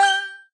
respawn_count_01.ogg